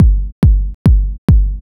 KICK016_TEKNO_140_X_SC2.wav